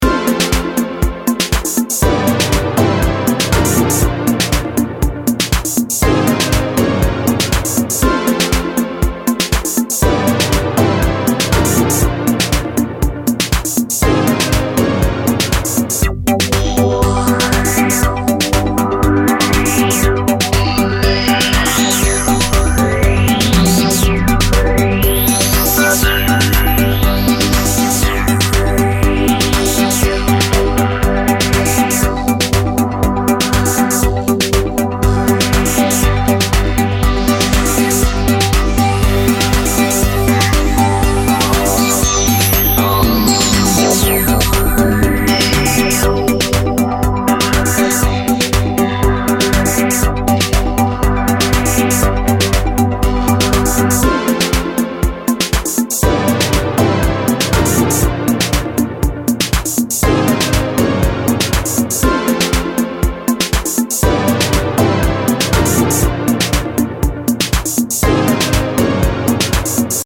I got my Roland Jupiter-6 back via this U-HE plugin.